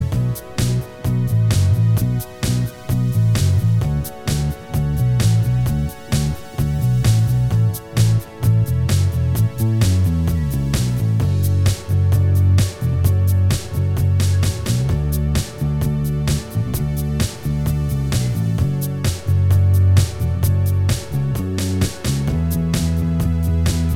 Minus Guitars Rock 4:30 Buy £1.50